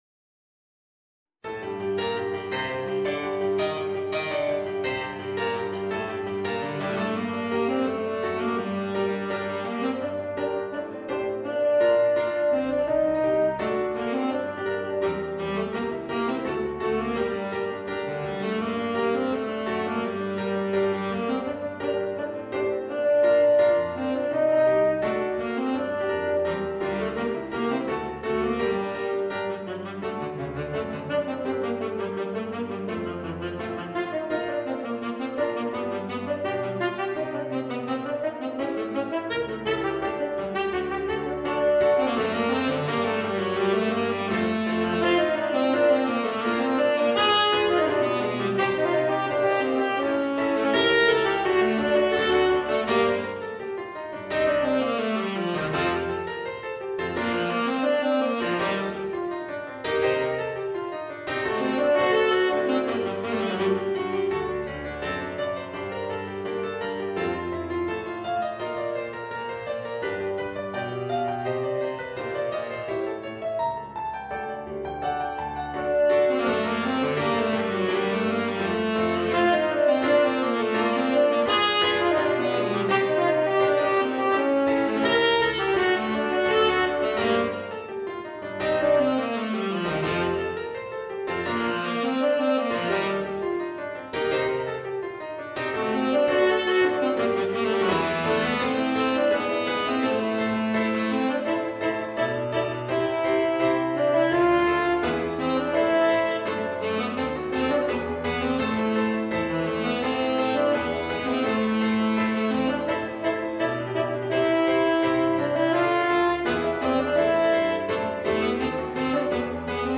Voicing: Tenor Saxophone and Piano